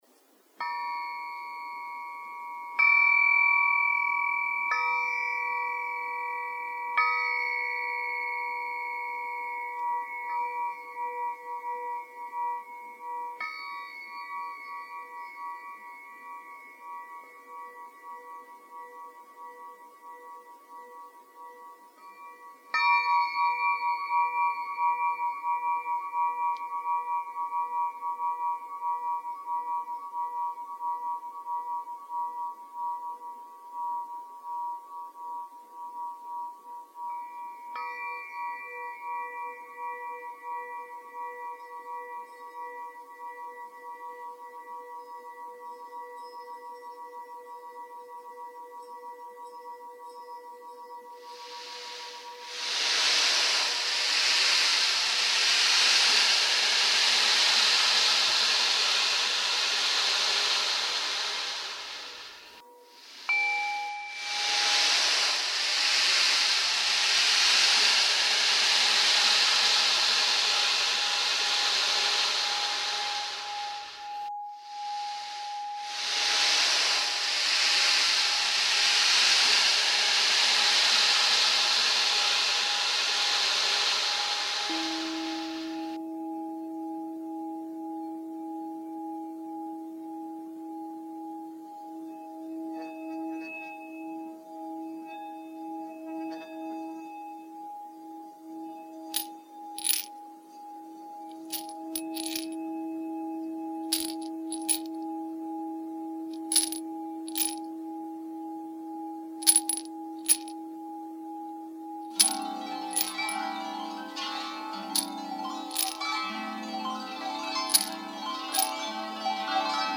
In this 6-mssinute meditation, the melody changes, and you drift into sleep.
6″ Crystal Singing Pyramid
Ocean Drum
4″ Tibetan Singing Bowl
6″ Tibetan Full Moon Singing Bowl
Ceramic Heart Rattle
Moon Harp
Double Strung Harp
Nova Double Flute in A Minor
Bowed Psaltery
31679-healing-sleep-meditation.mp3